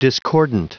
Prononciation du mot discordant en anglais (fichier audio)
Prononciation du mot : discordant